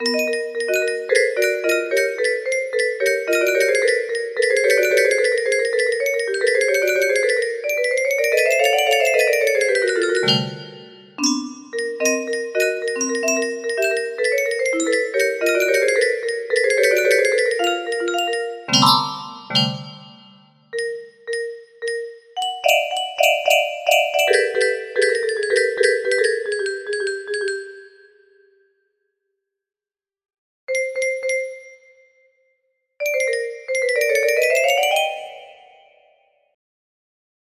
Weird Song. music box melody